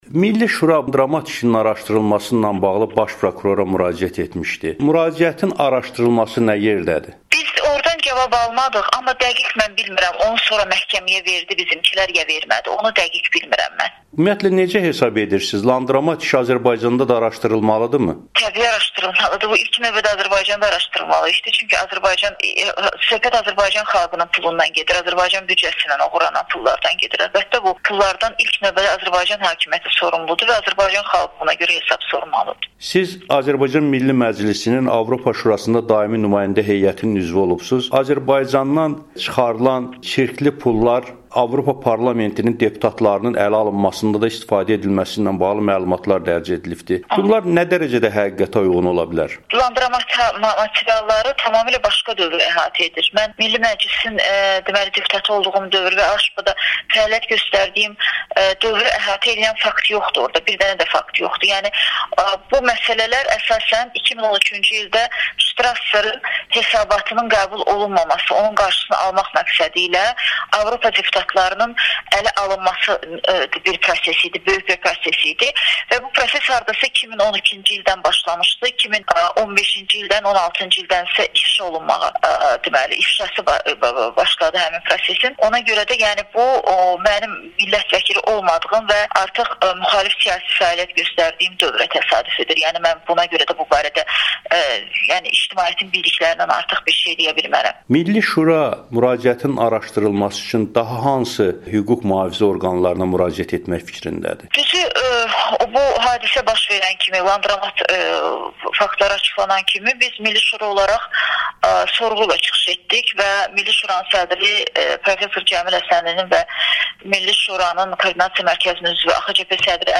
Demokratik Qüvvələrin Milli Şurasının Koordinasiya Mərkəzinin üzvü, Milli Məclisin Avropa Şurası Parlament Assambleyasında (AŞPA) daimi nümayəndə heyəinin üzvü olmuş Gültəkin Hacıbəyli Amerikanın Səsinə müsahibəsində deyib ki, Milli Şuranın landromatla bağlı müraciətinə prokurorluq cavab verməyib.